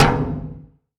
ANI Big Pipe Hit
can collision crash door drop hit impact metal sound effect free sound royalty free Music